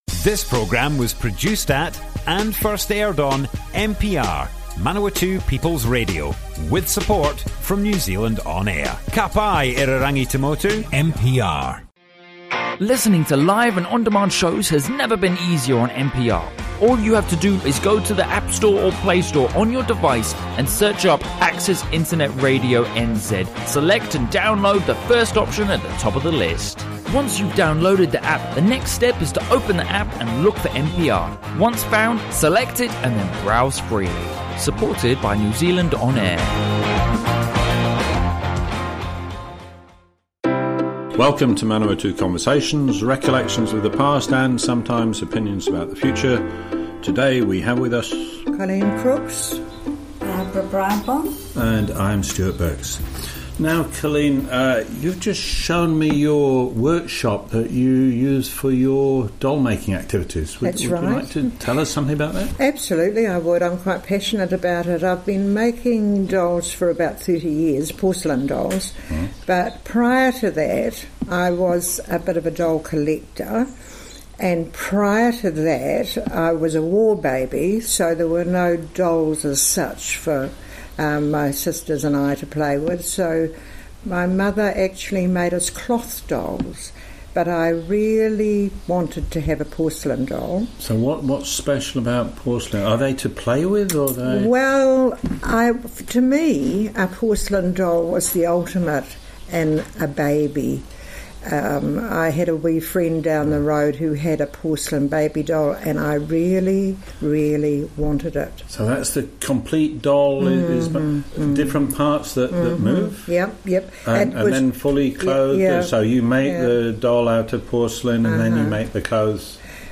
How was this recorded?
Broadcast on Manawatu People's Radio 15 January 2019.